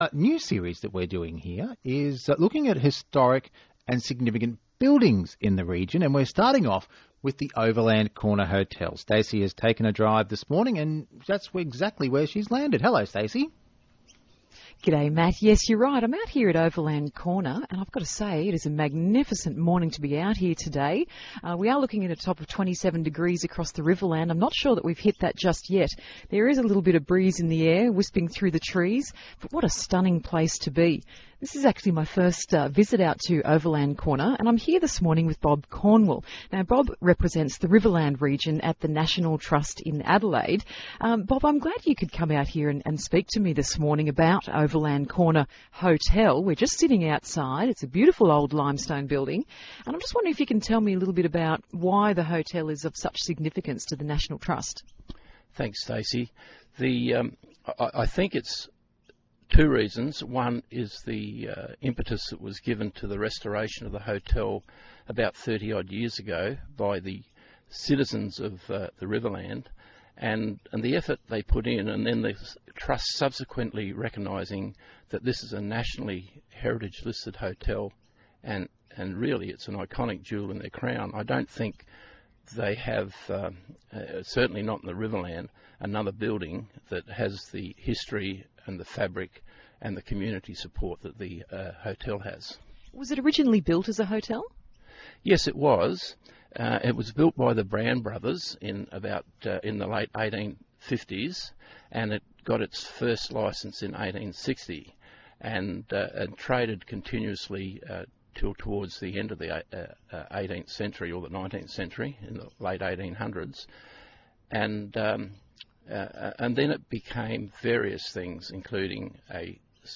Historic and Significant Buildings; ABC Riverland radio broadcast interview